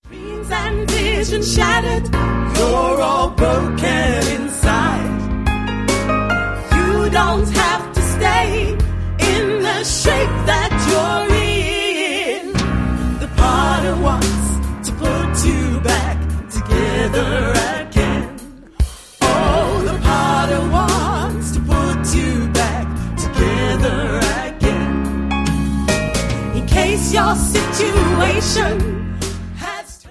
• Sachgebiet: Black Gospel